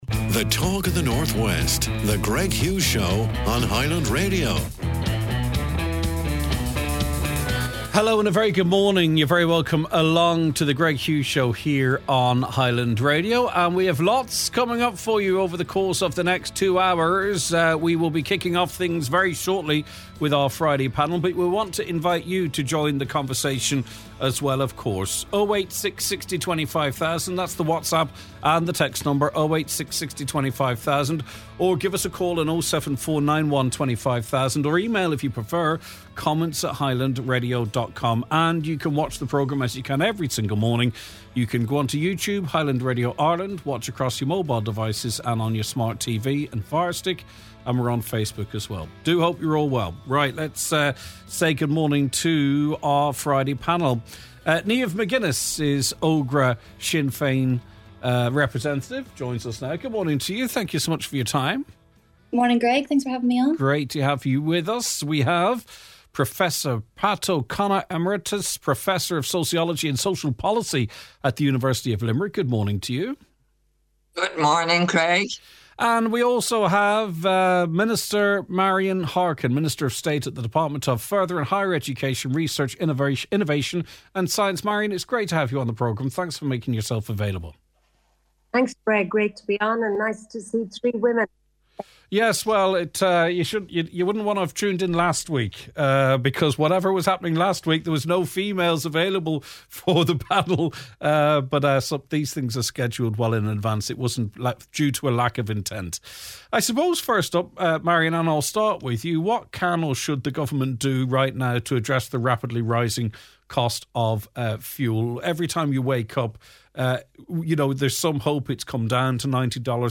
The Cost of Living: As fuel prices continue to squeeze households, the panel debates what immediate actions the Government must take to alleviate the burden on motorists and families.